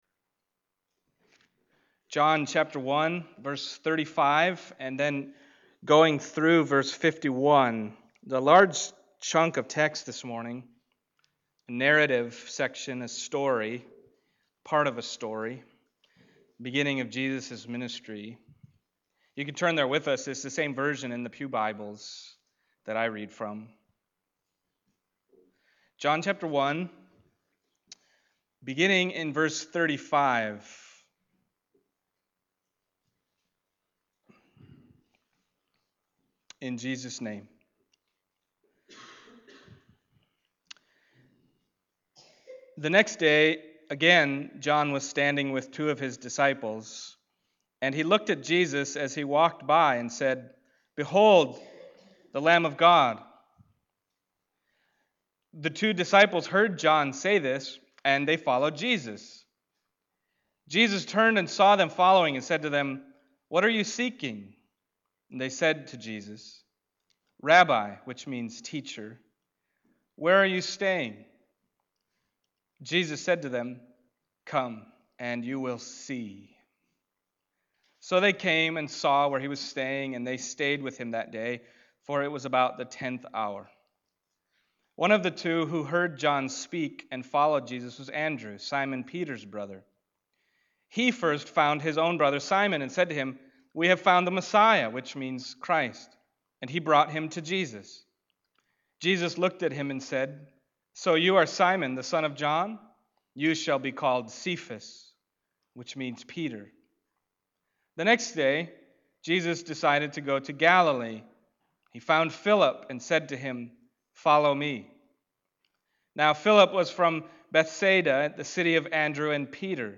John Passage: John 1:35-51 Service Type: Sunday Morning John 1:35-51 « Bearing Witness That Jesus is the Son of God Messiah